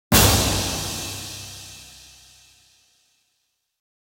下のサンプルは、手を加えていない状態のシンバルと、16kHz以上をカットしたシンバルです。
元のシンバルのトゥルーピークは+0.8dBですが、16kHz以上をカットしたシンバルは-0.1dBになっています。
※音量注意。結構大きいです。
・16kHz以上をカットしたシンバル（-0.1dBTP/-15.5LUFS）
超高域をカット
cymbal-tp-sample-after.wav